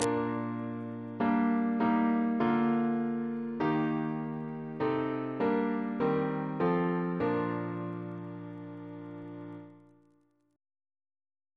CCP: Chant sampler
Single chant in B♭ Composer: Edwin George Monk (1819-1900), Organist of York Minster Reference psalters: ACB: 37; ACP: 196; H1940: 613 651; OCB: 57; PP/SNCB: 159; RSCM: 196